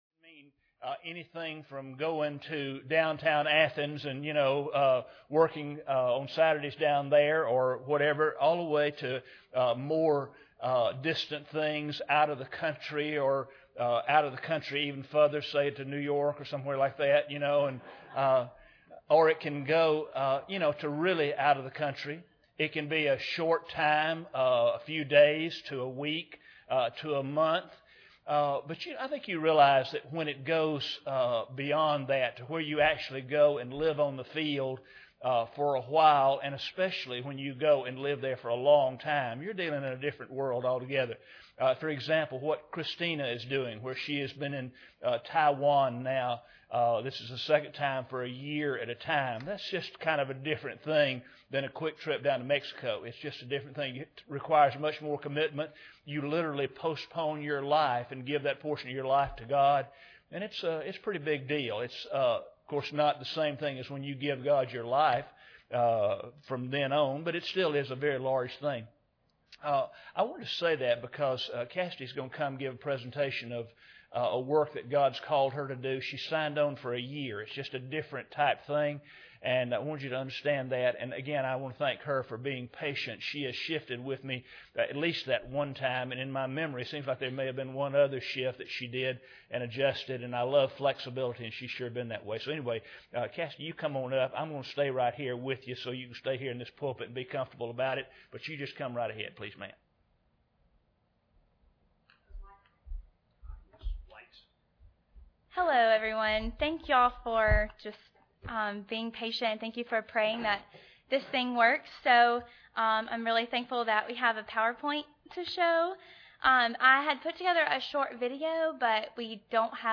General Service Type: Sunday Evening Preacher